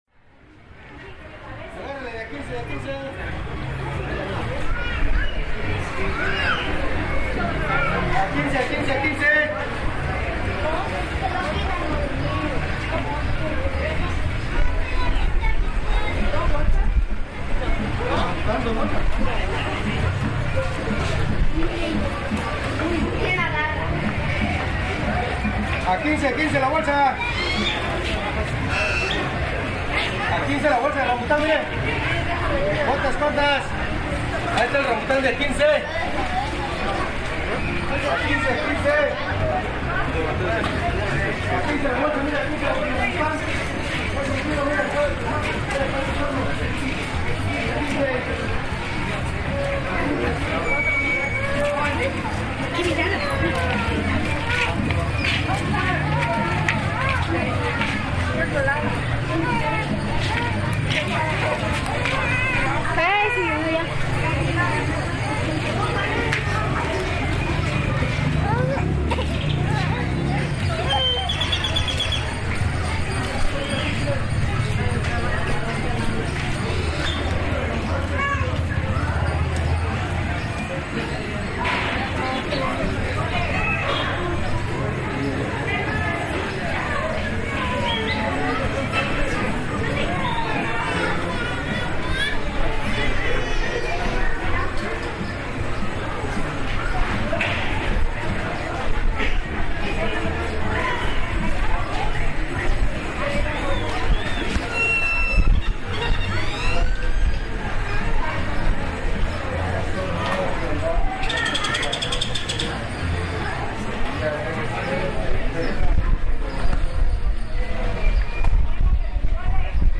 ¿Cómo suena el parque central de Berrizábal los domingos? Hay mucho que ver, que escuchar, que comprar. La plaza es una fiesta de colores y sonidos.